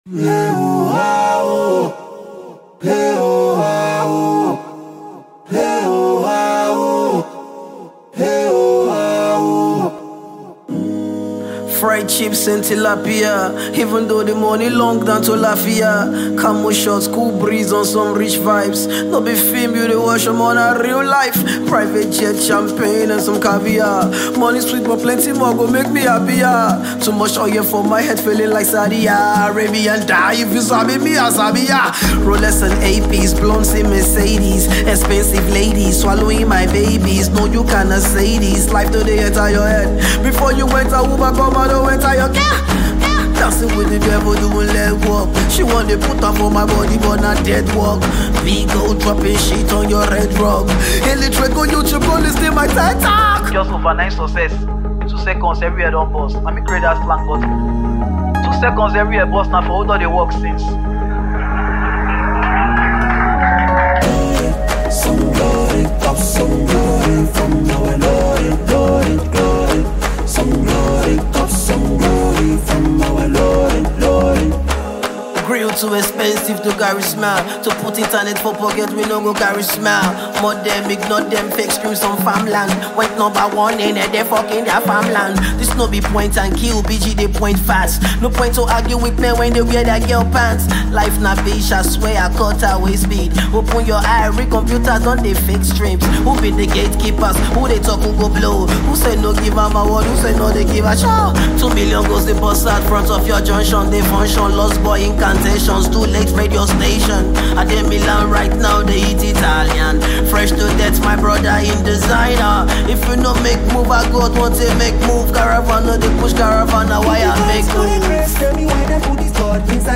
freestyle track
rap